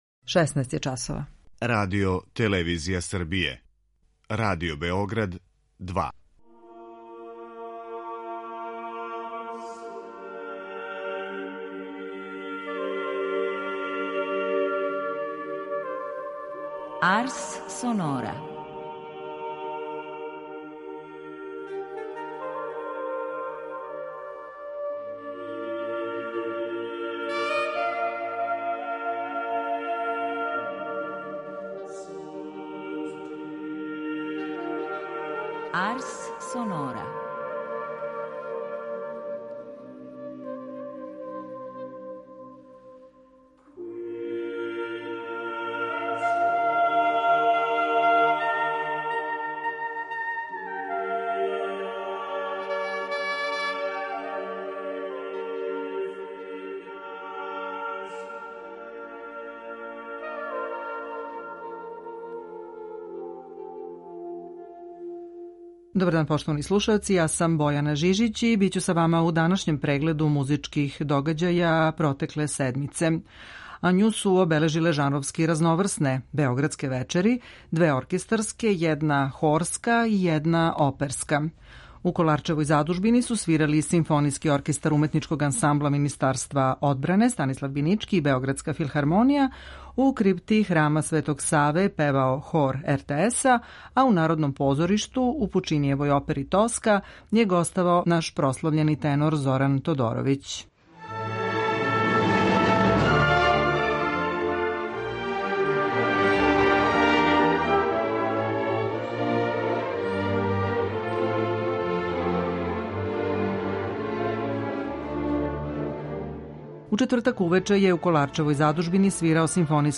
Моћи ћете да чујете како су у Коларчевој задужбини свирали Симфонијски оркестар Уметничког ансамбла Министарства одбране Станислав Бинички и Београдска филхармонија